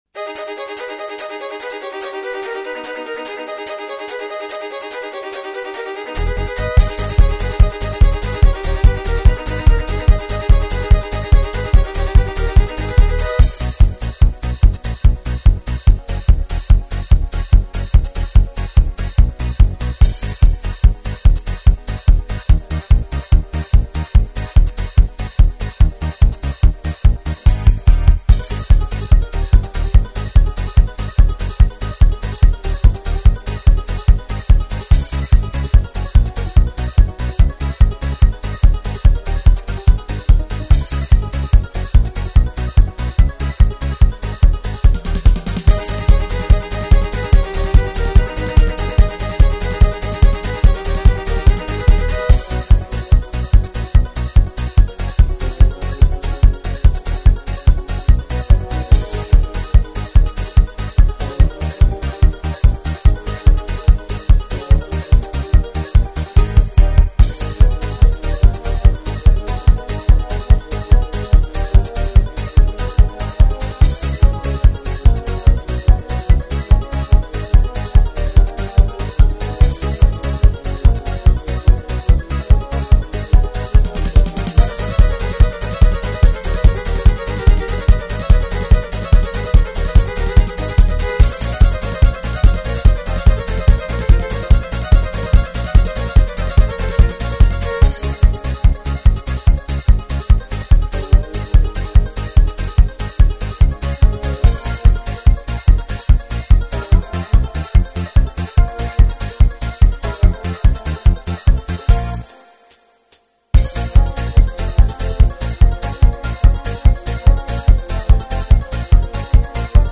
минусовка версия 33211